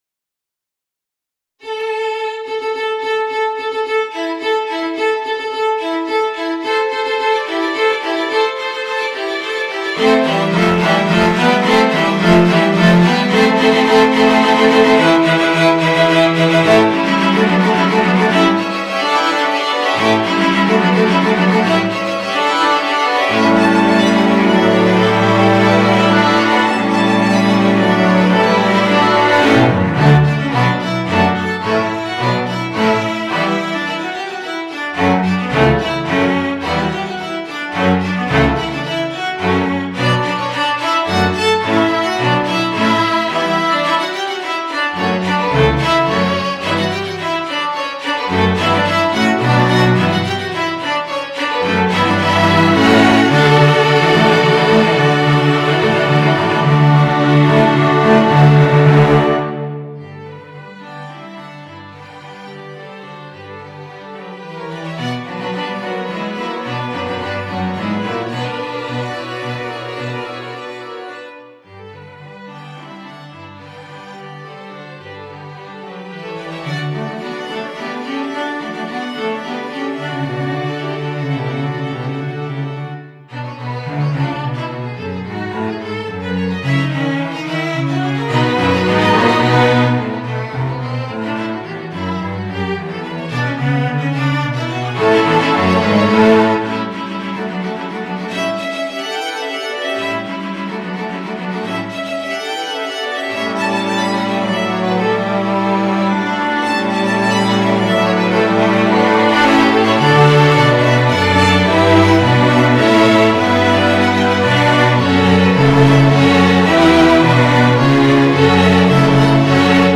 Voicing: String Orchestra and Organ